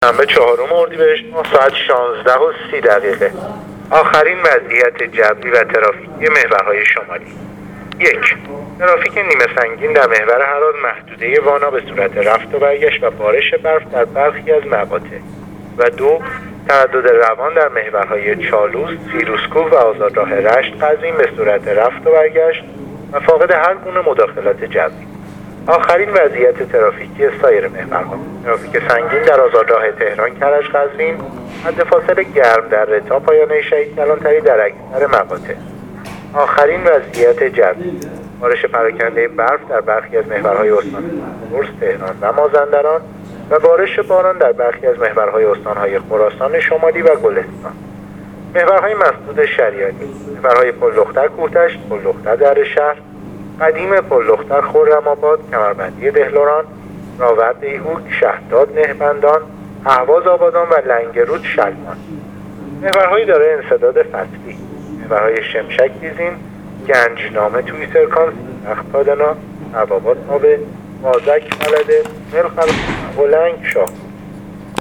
گزارش رادیو اینترنتی وزارت راه و شهرسازی از آخرین وضعیت‌ ترافیکی راه‌های کشور تا ساعت ۱۶:۳۰ چهارم اردیبهشت/ترافیک سنگین در محور تهران-کرج-قزوین/ترافیک نیمه‌سنگین در محور هراز